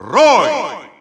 Announcer pronouncing Roy Koopa in Russian.
Roy_Koopa_Russian_Announcer_SSBU.wav